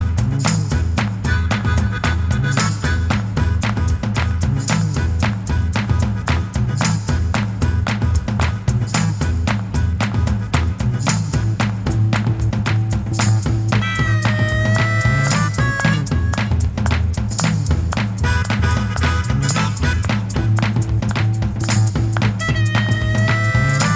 Minus Lead Guitar Rock 1:58 Buy £1.50